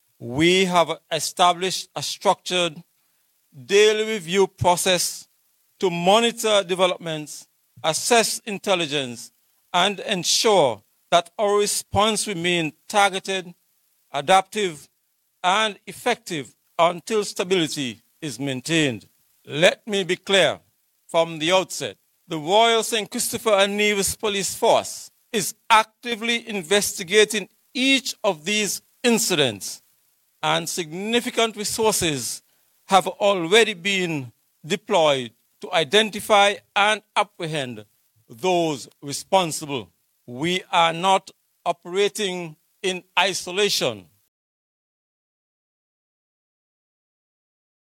Following the most recent murders in St. Kitts, Commissioner of Police (COP), James Sutton, spoke of plans to deal with the spike in violent criminal activity on Monday Apr. 20th.
Nonetheless, during the Police Force’s press conference on Apr. 20th, Mr. Sutton mentioned further efforts to help reduce criminal activity.